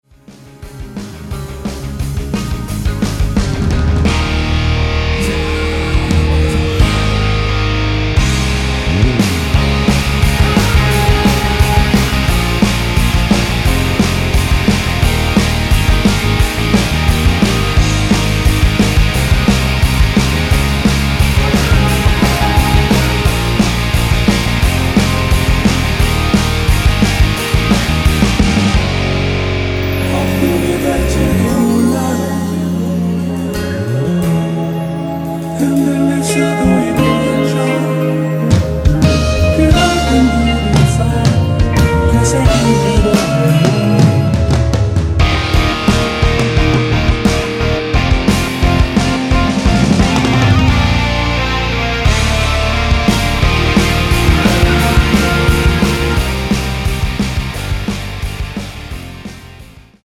(-2) 내린 코러스 포함된 MR 입니다.
원곡의 보컬 목소리를 MR에 약하게 넣어서 제작한 MR이며